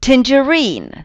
tangerine [‚tændžə’ri:n]